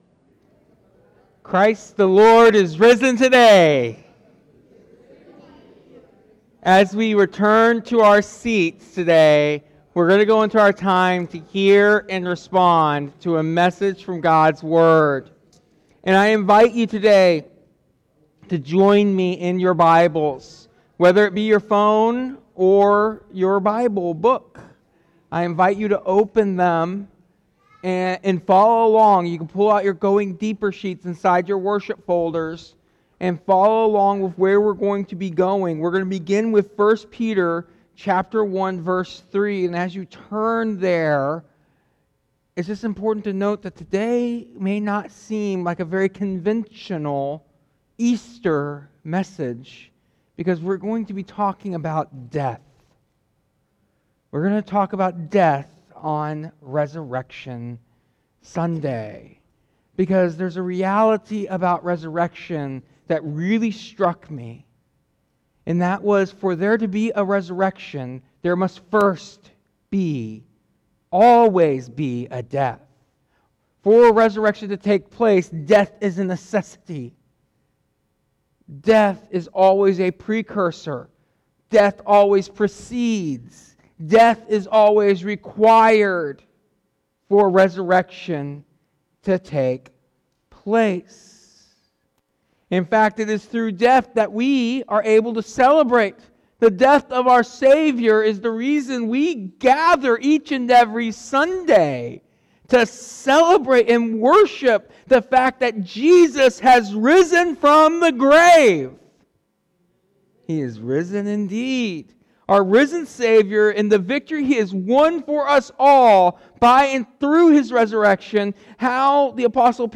This Easter sermon emphasizes that Jesus’ resurrection brings not only a future hope but a present “living hope” (1 Peter 1:3).